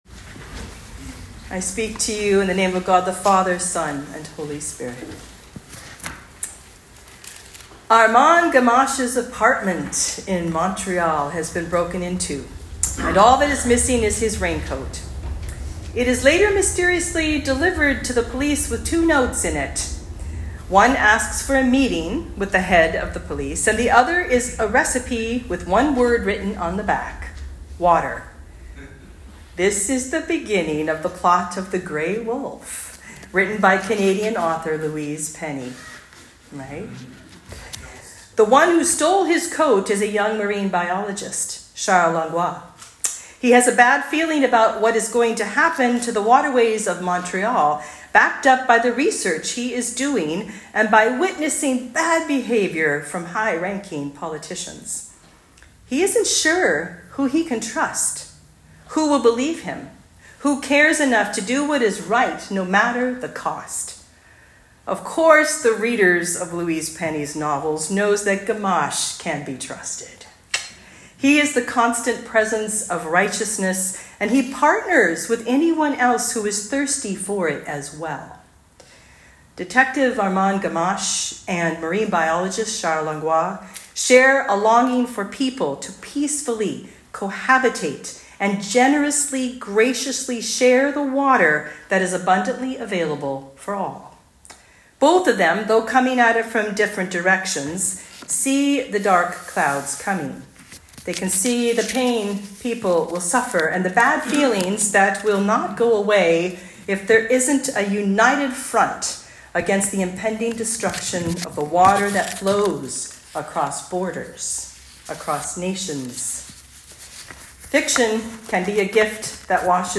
Sermons | Holy Trinity North Saanich Anglican Church